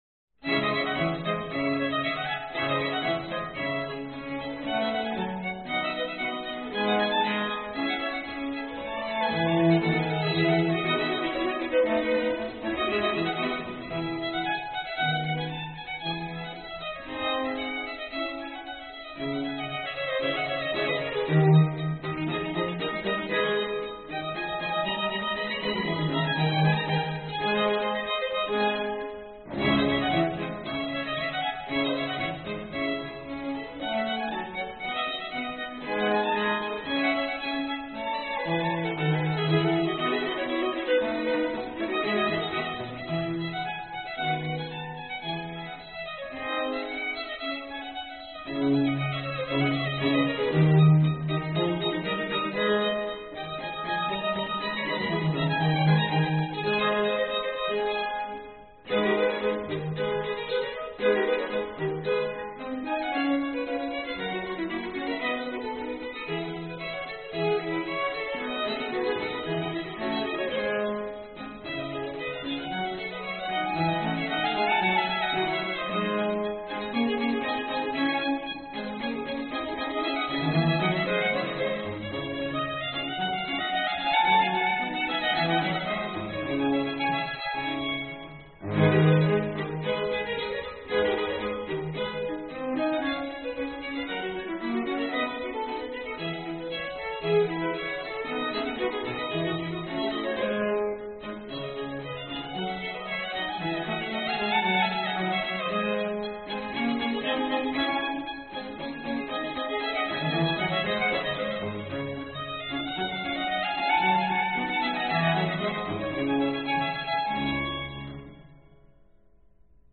Genre : musique de chambre
Corellien, mais plus libre